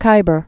(kībər)